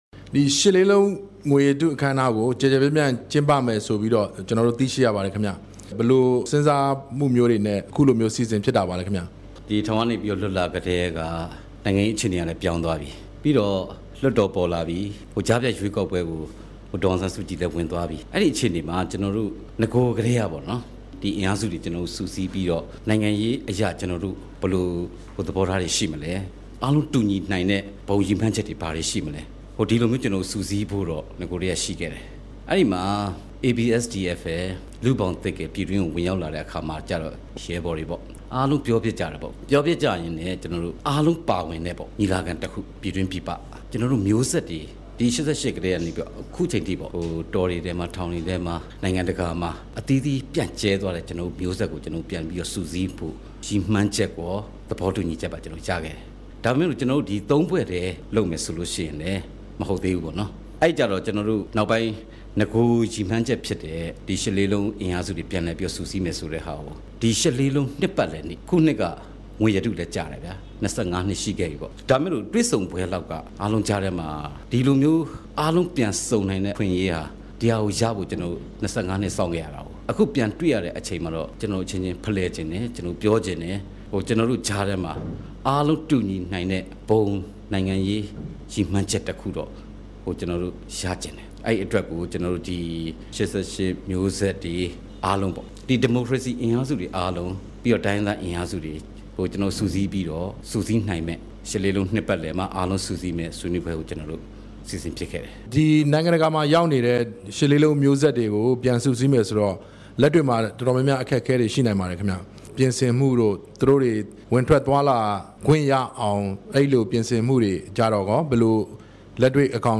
ရှစ်လေးလုံး ငွေရတု နှစ်ပတ်လည်နေ့ကျင်းပရေး တွေ့ဆုံမေးမြန်းချက်